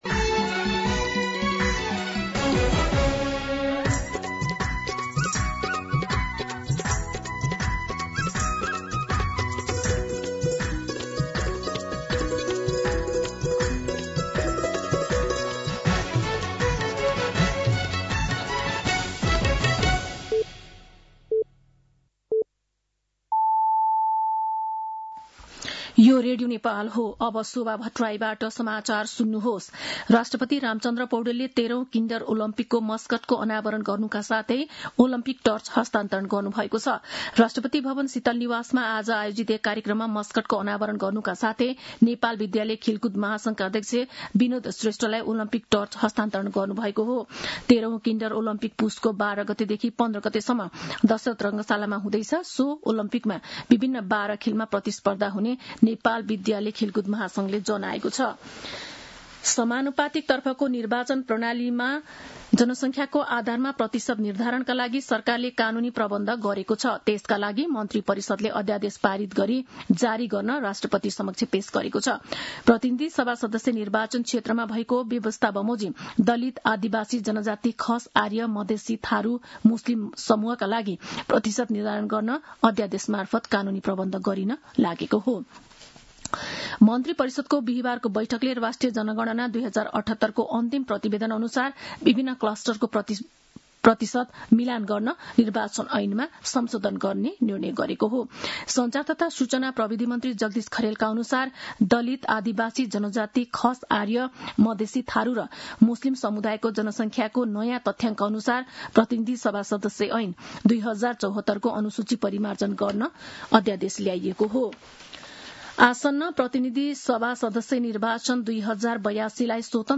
दिउँसो ४ बजेको नेपाली समाचार : ४ पुष , २०८२